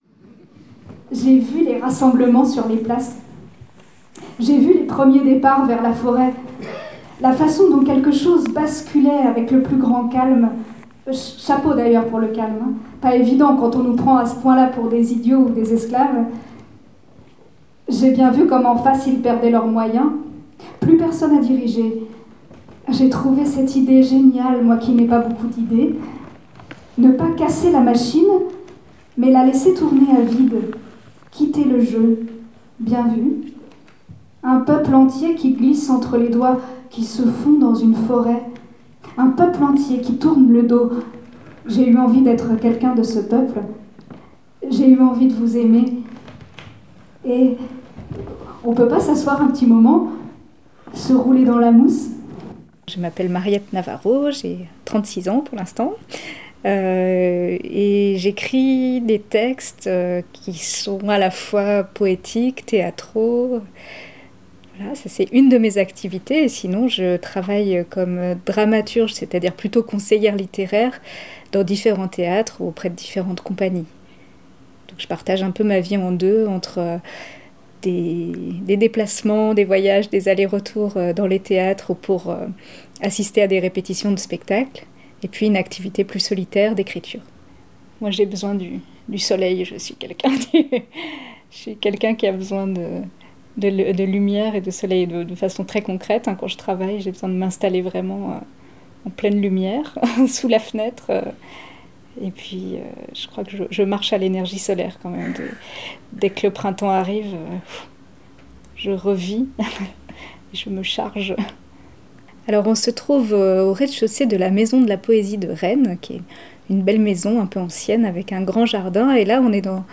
Pour commencer l’année, je vous fais part d’un choix qui peut surprendre : revisitons 2017 en compagnie de quatre entretiens avec des poètes que Radio Univers a rencontré dans l’année.